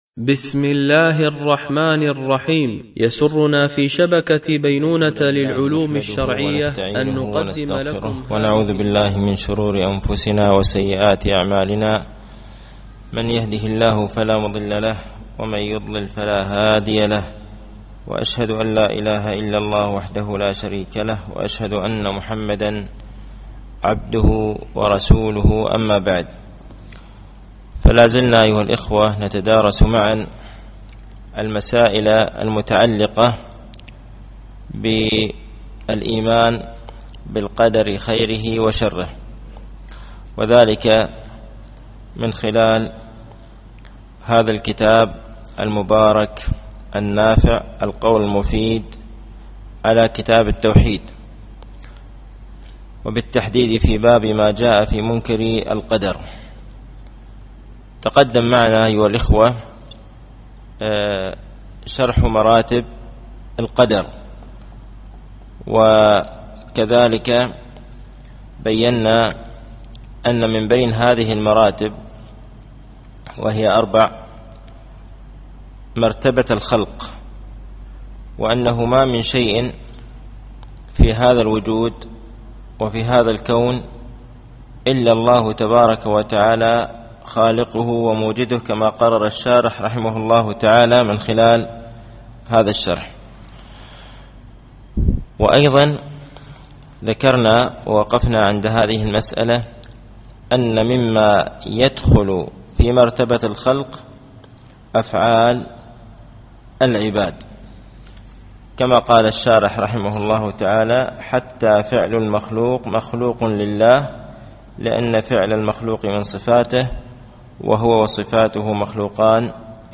التعليق على القول المفيد على كتاب التوحيد ـ الدرس السادس و الخمسون بعد المئة